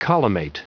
Prononciation du mot collimate en anglais (fichier audio)
Prononciation du mot : collimate